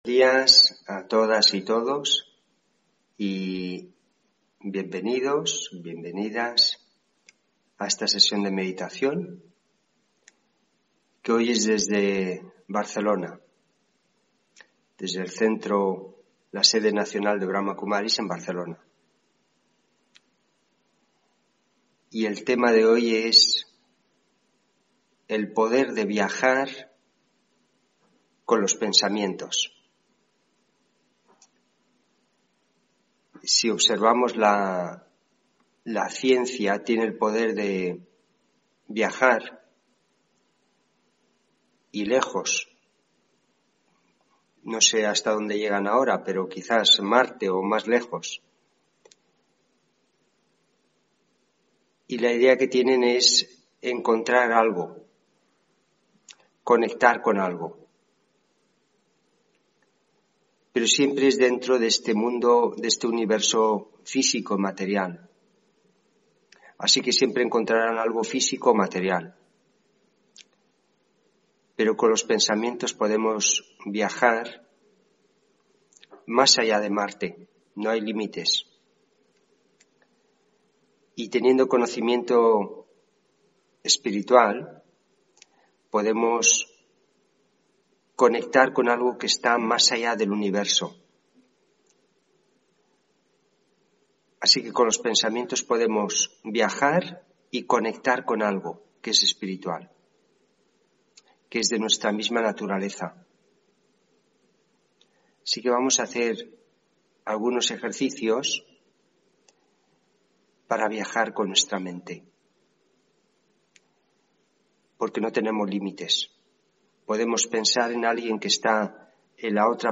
Meditación de la mañana Viajar con los pensamientos